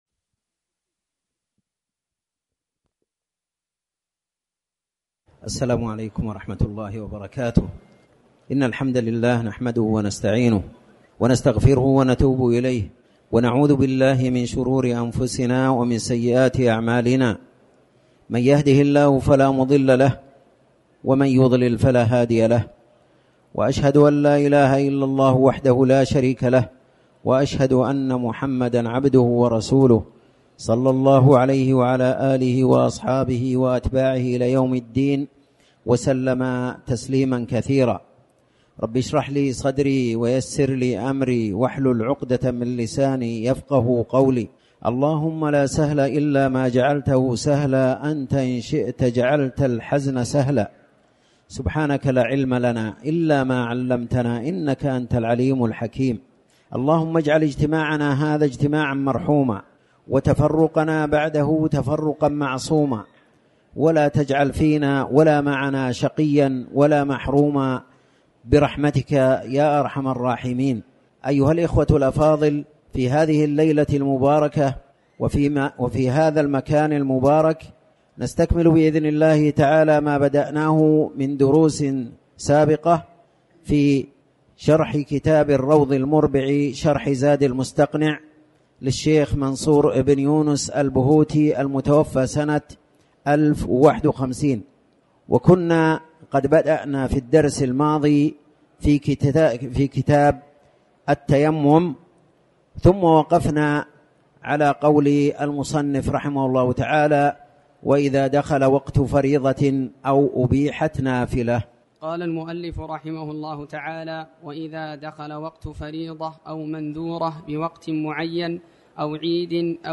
تاريخ النشر ٢٤ ربيع الثاني ١٤٤٠ هـ المكان: المسجد الحرام الشيخ